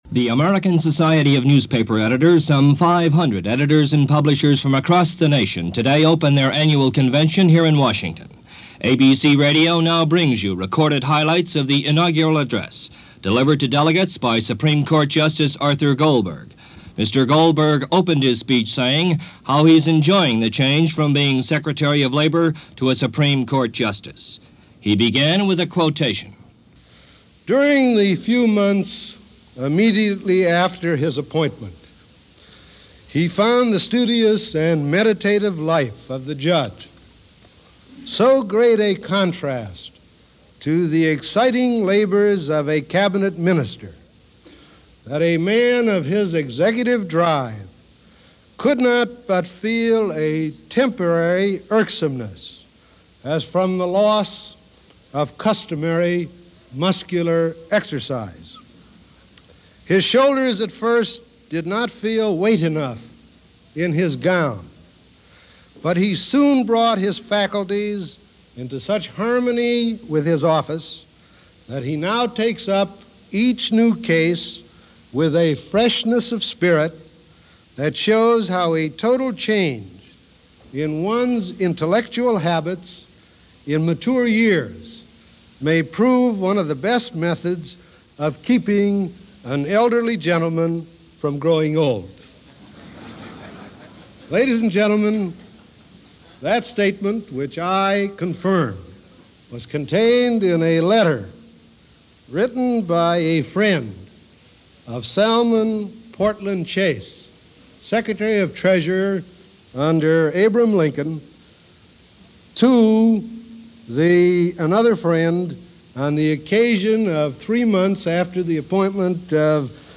Justice Arthur Goldberg - American Society Of Newspaper Editors - 1964 - Past Daily Reference Room - Recorded April 17, 1964 - ABC Radio
Supreme Court Justice Arthur Goldberg spoke at a gathering of The American Society of Newspaper Editors in Washington on April 17, 1964.